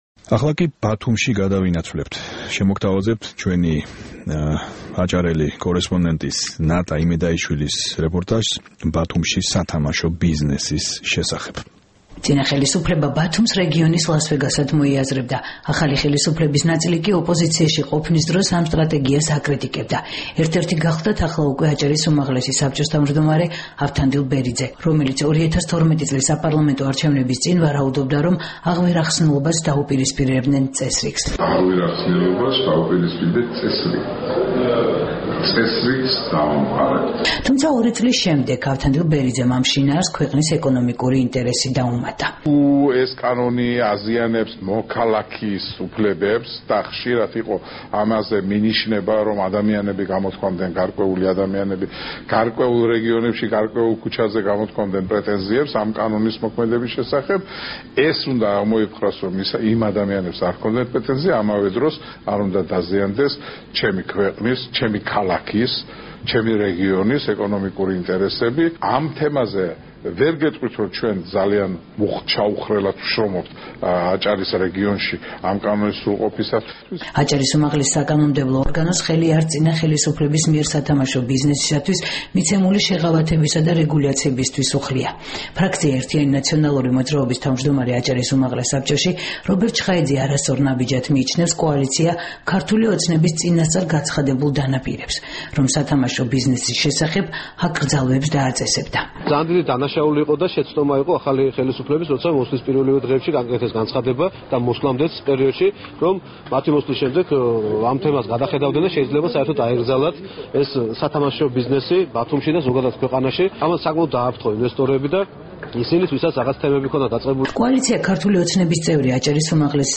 რეპორტაი ბათუმიდან